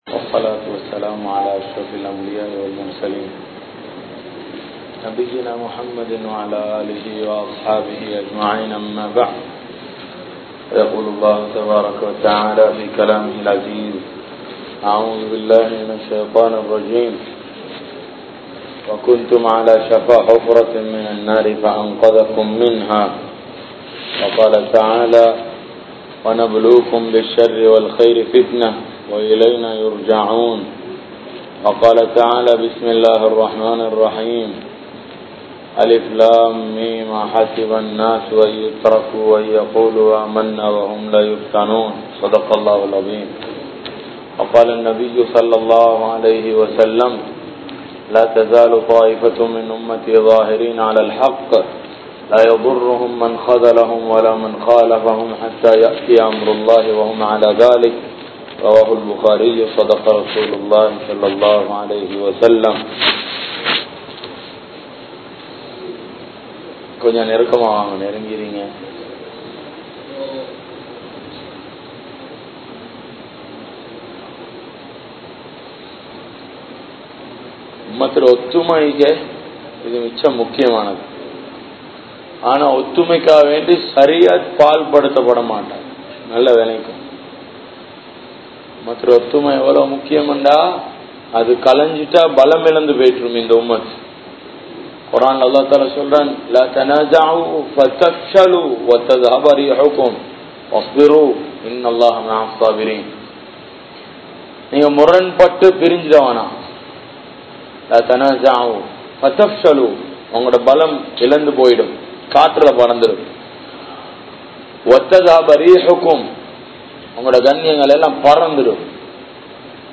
Ottrumaium Muranpaadum (ஒற்றுமையும் முரன்பாடும்) | Audio Bayans | All Ceylon Muslim Youth Community | Addalaichenai
Colombo 12, Aluthkade, Muhiyadeen Jumua Masjidh